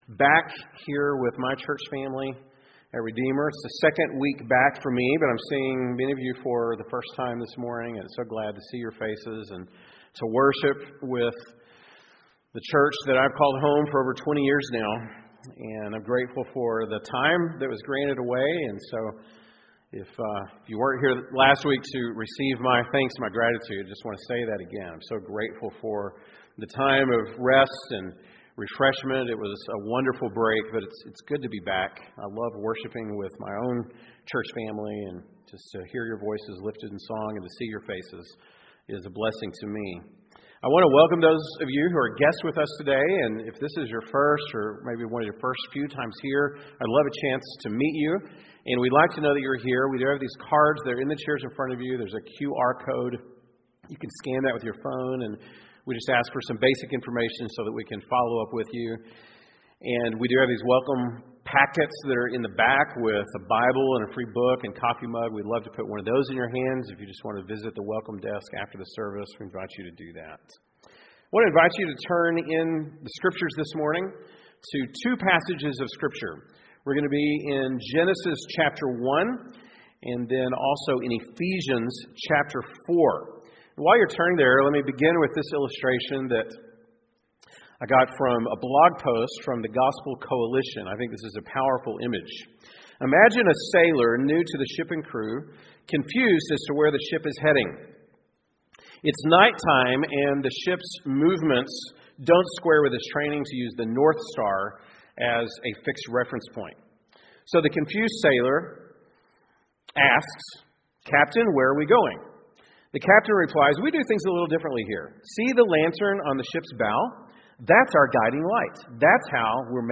September 10, 2023 (Sunday Morning)